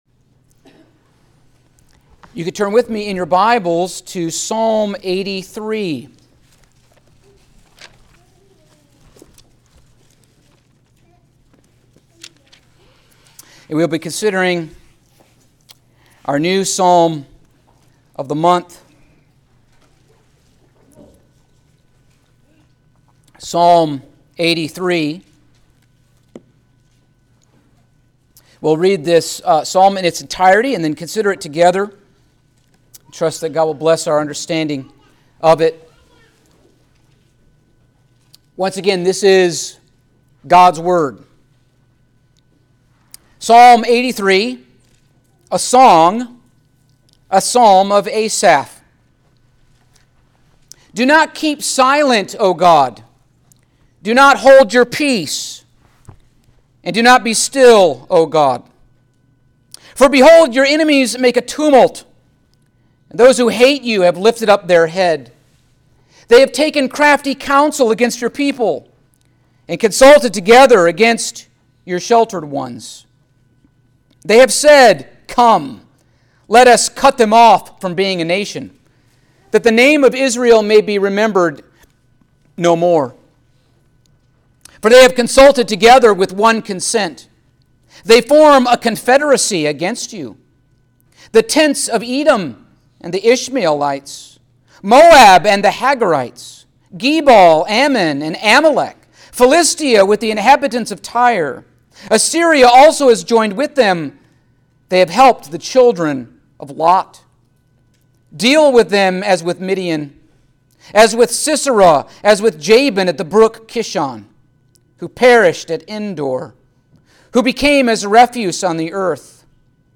Passage: Psalm 83 Service Type: Sunday Morning